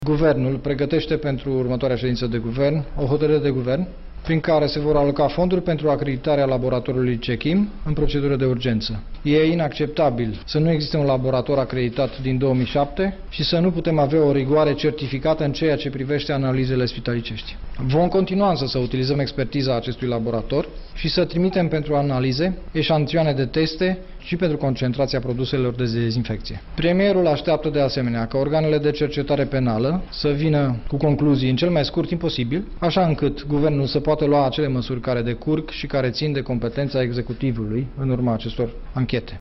Purtătorul de cuvînt al Guvernului, Dan Suciu: